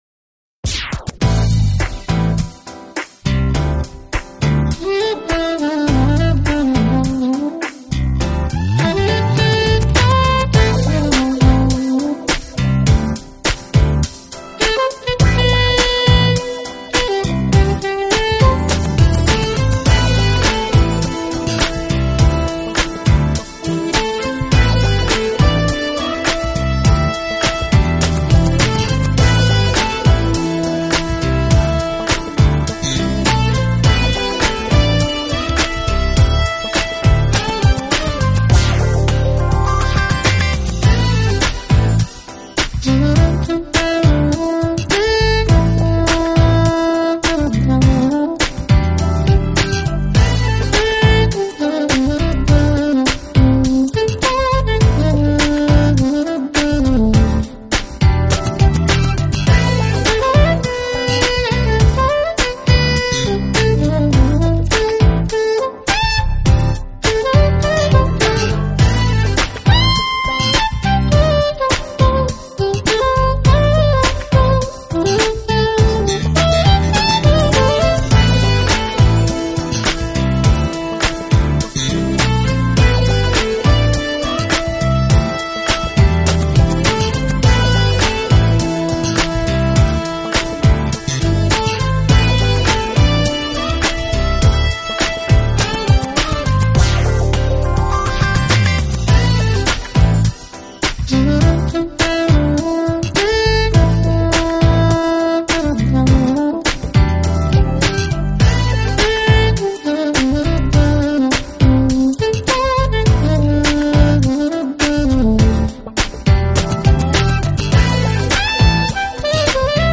音乐类型：Smooth Jazz
就是一个专门吹奏高音SAX的Smooth Jazz乐手，为了显示他不象其它SAX手兼吹
息温暖而润泽，带着优雅的气质和鲜活的生命力，而伴奏的吉他则飘逸清爽，具
有极佳的穿透力。另外，由于采用的是小乐队方式演奏，所以乐器的分离度非常
高，层次感鲜明，定位也相当清楚。